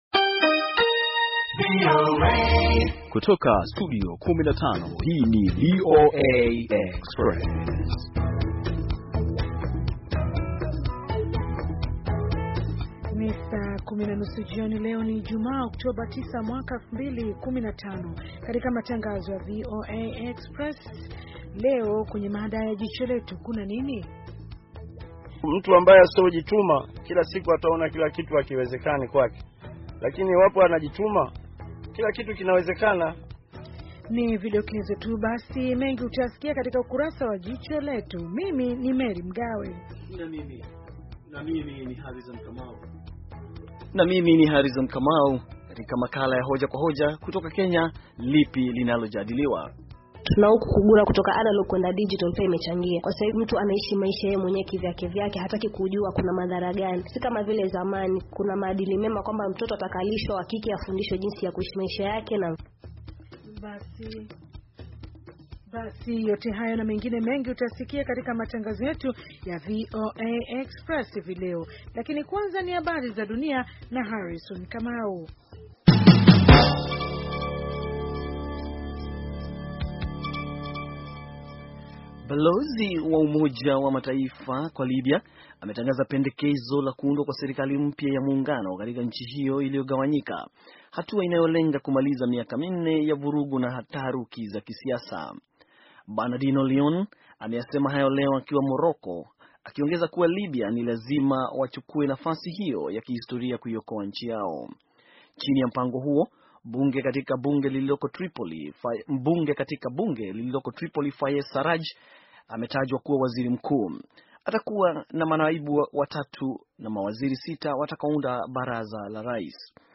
Taarifa ya habari - 5:41